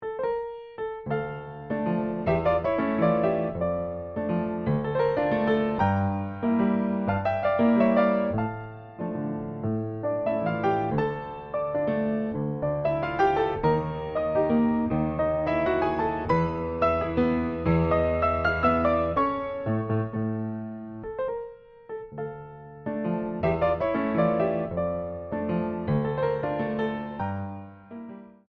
Valzer venezuelani del XIX sec.
Pianoforte
Registrazione audiophile realizzata nei mesi di agosto e settembre 2005 con microfoni e pre-amplificatore a valvole, campionamento a 96 kHz.